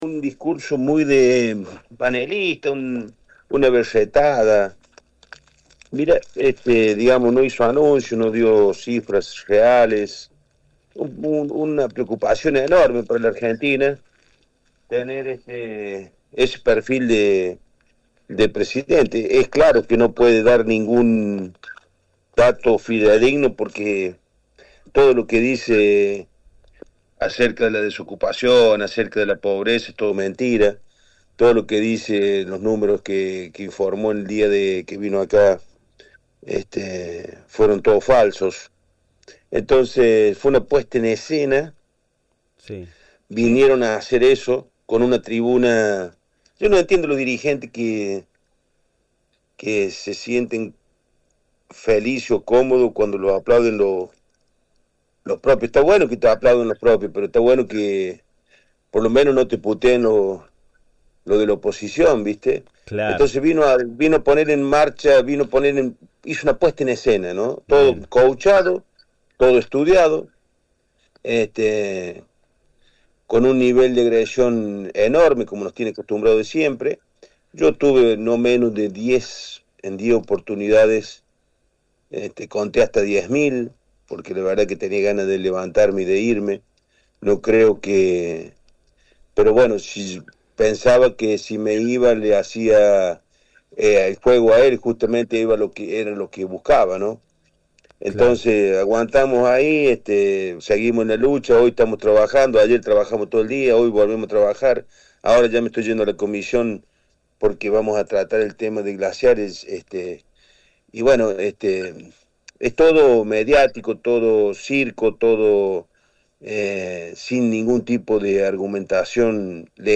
En diálogo con Radio UNSL Villa Mercedes 97.5 FM, el diputado nacional por San Luis Ernesto “Pipi” Alí criticó duramente al presidente Javier Milei tras su reciente discurso y se refirió a la realidad política provincial y nacional.